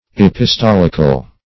Search Result for " epistolical" : The Collaborative International Dictionary of English v.0.48: Epistolic \Ep`is*tol"ic\, Epistolical \Ep`is*tol"ic*al\, a. [L. epistolicus, Gr. ?.] Pertaining to letters or epistles; in the form or style of letters; epistolary.